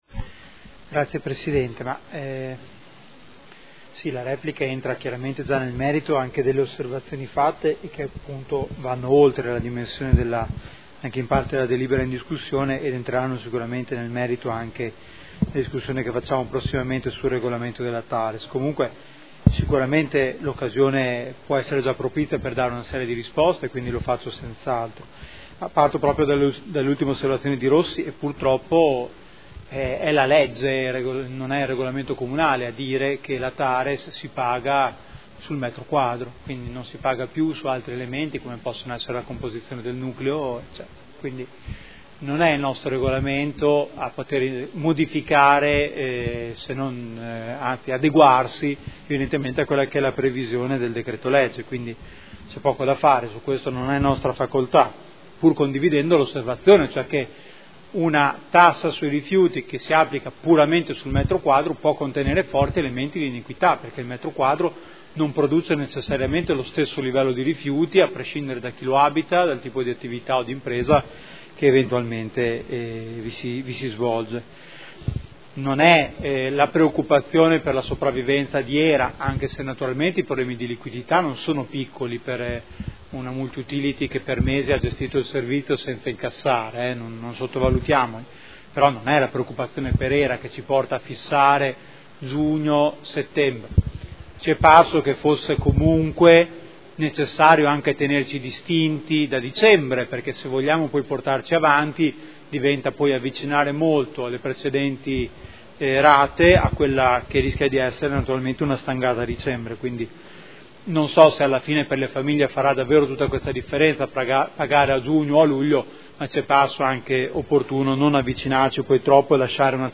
Seduta del 20/05/2013.